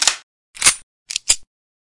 手枪上膛的声音
描述：我在Freesound上发现了几种重装武器的声音，切断了它们并将其固定在Audacity上。